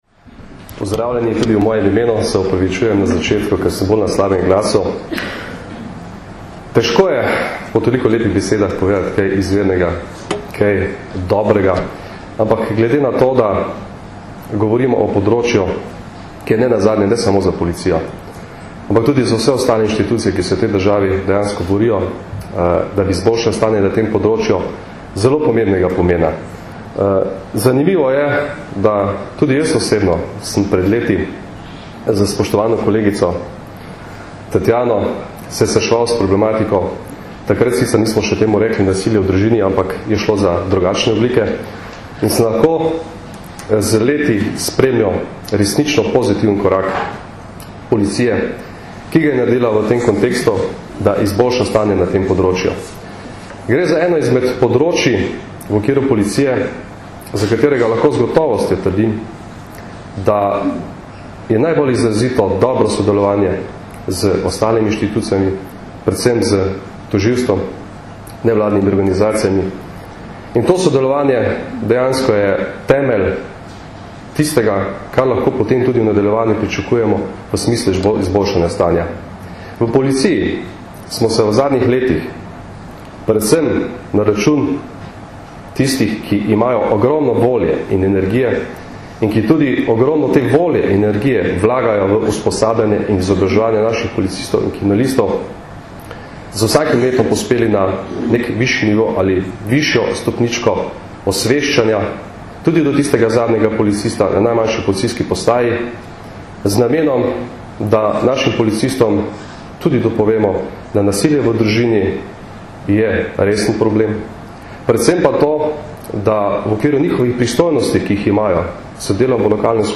Na današnji otvoritvi so udeležence z uvodnimi besedami pozdravili v. d. generalnega direktorja policije Janko Goršek in ministrica za notranje zadeve Katarina Kresal, varuhinja človekovih pravic dr. Zdenka Čebašek Travnik, generalna državna tožilka Barbara Brezigar, državni sekretar na Ministrstvu za pravosodje Boštjan Škrlec in direktor Centra za izobraževanje v pravosodju Damijan Florjančič.
Govor v. d. generalnega direktorja policije Janka Gorška (mp3)